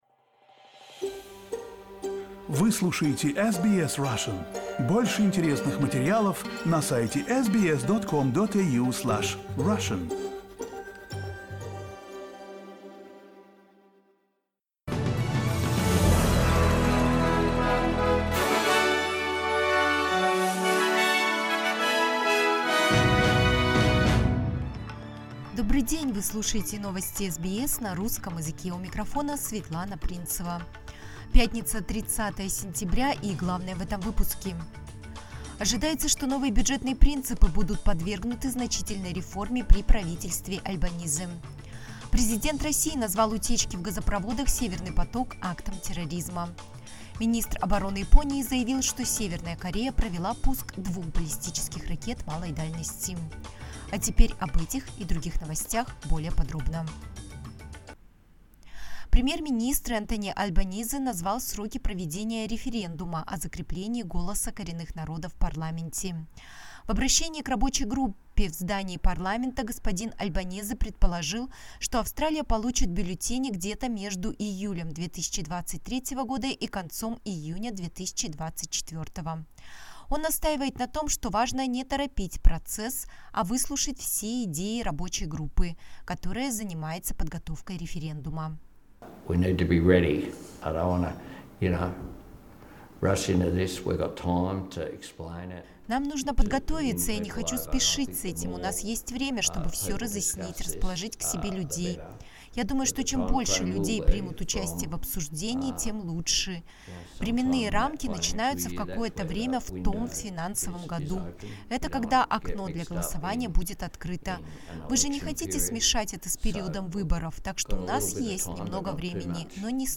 SBS news in Russian - 30.09.2022